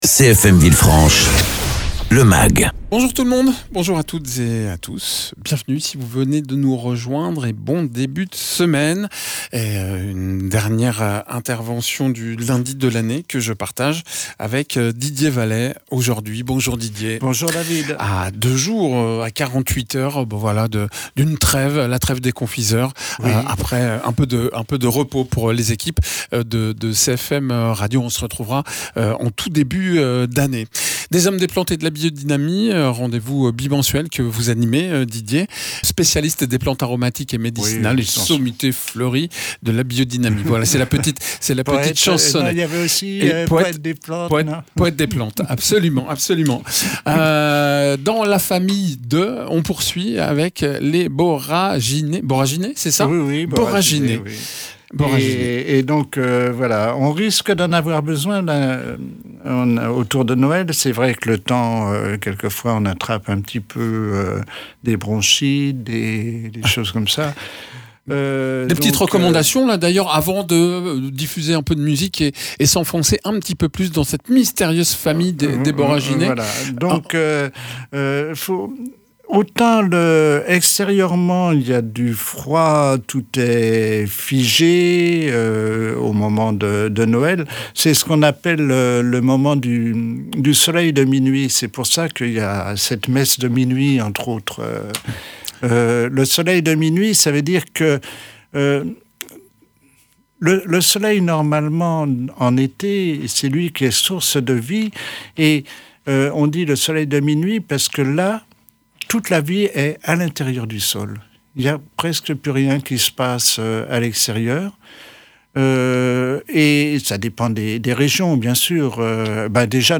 Dernier rendez-vous de l’année avec votre chronique bimensuelle du lundi,